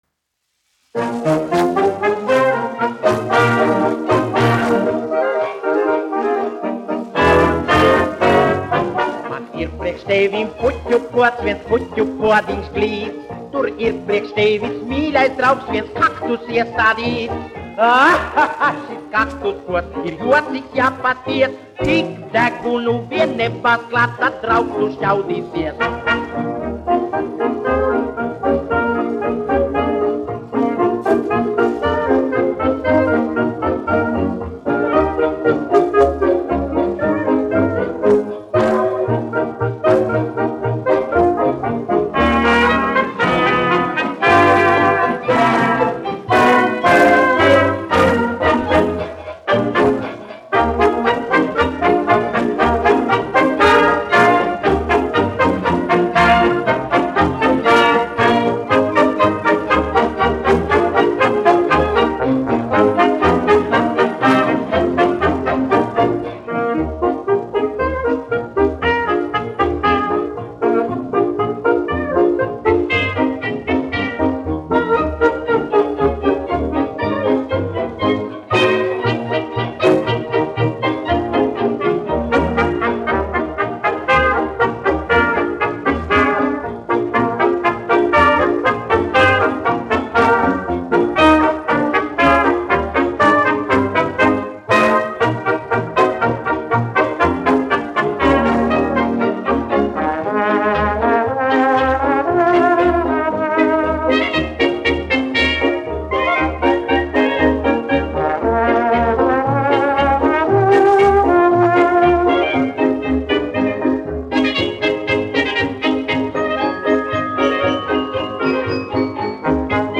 1 skpl. : analogs, 78 apgr/min, mono ; 25 cm
Populārā mūzika
Fokstroti
Humoristiskās dziesmas